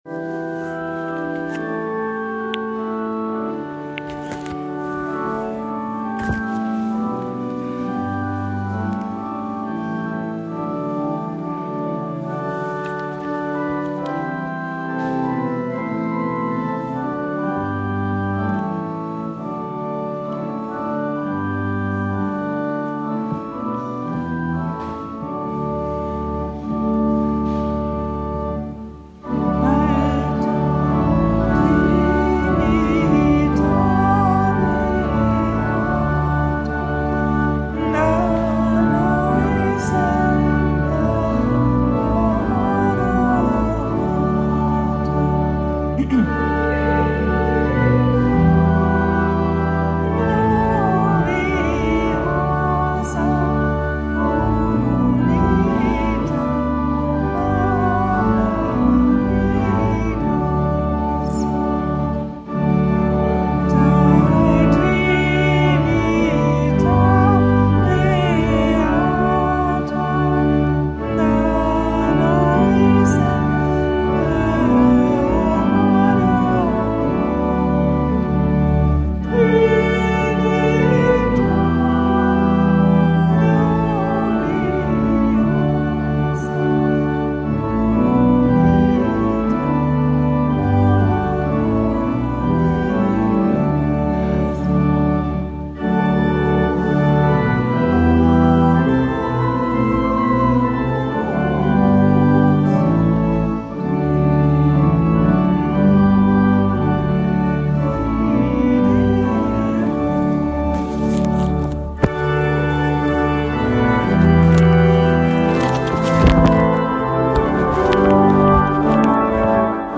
Predigt an Trinitatis